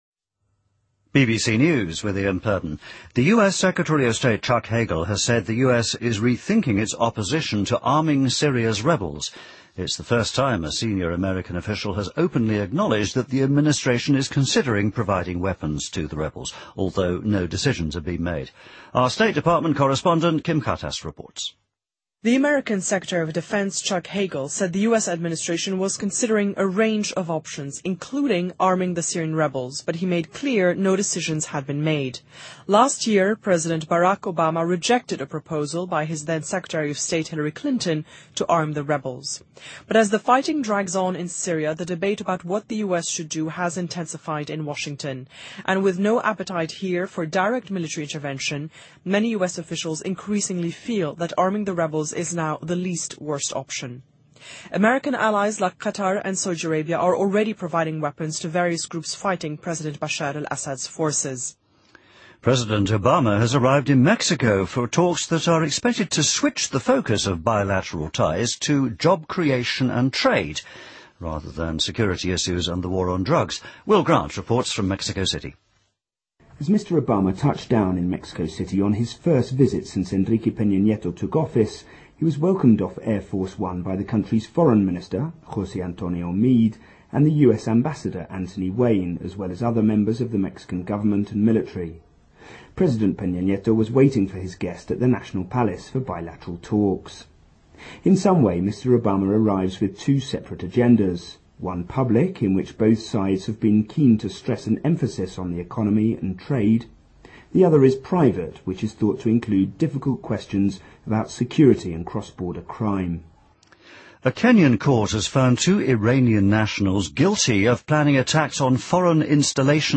BBC news,2013-05-03